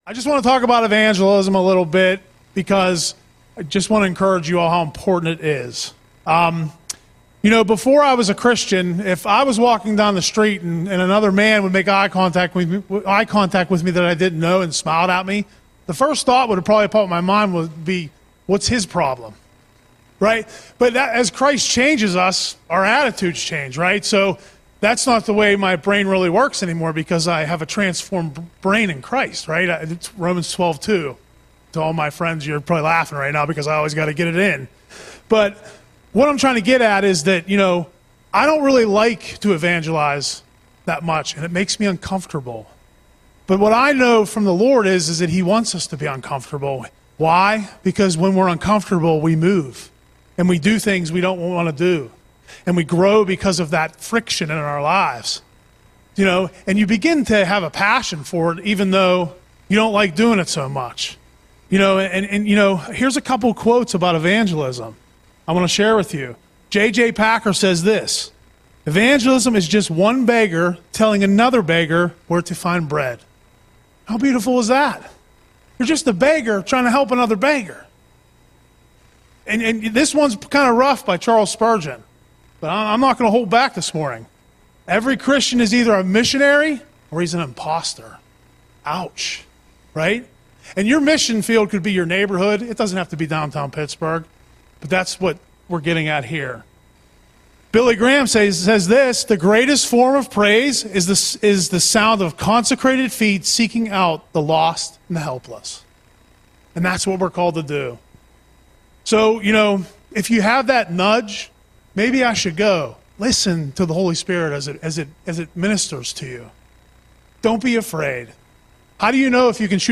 Audio Sermon - August 3, 2025